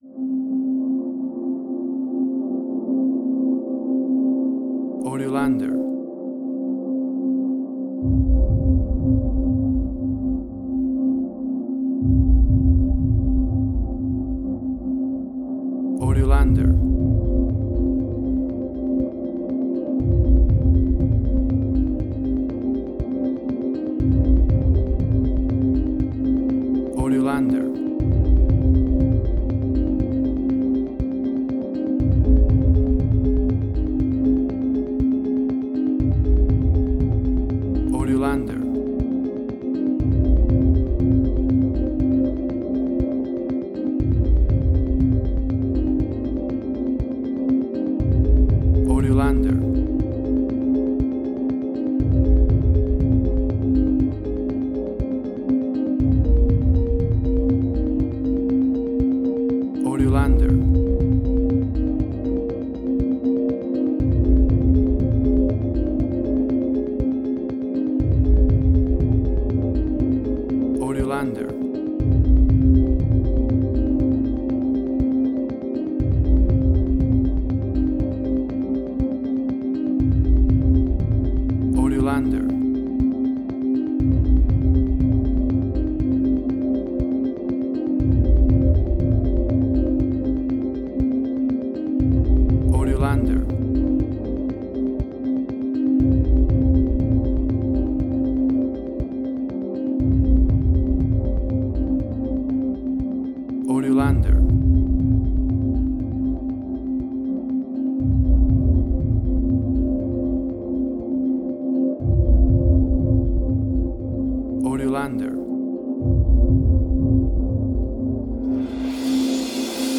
Post-Electronic.
Tempo (BPM): 120